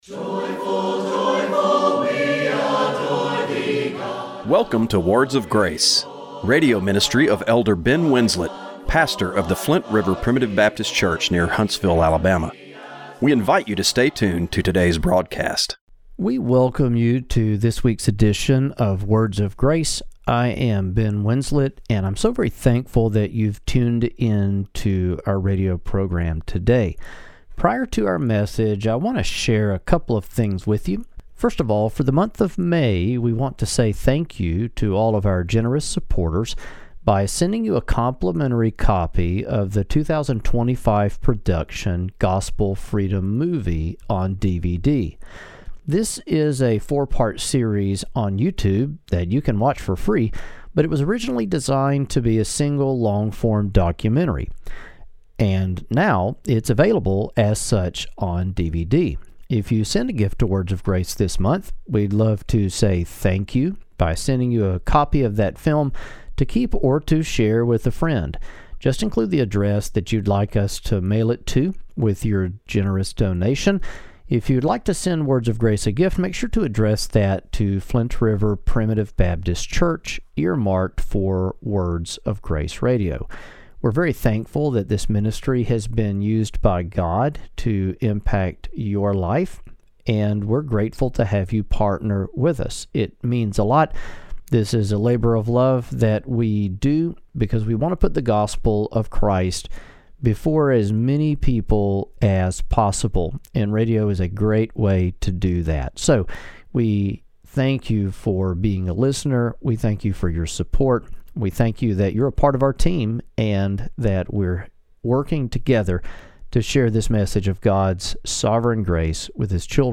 Radio Broadcast for May 11, 2025.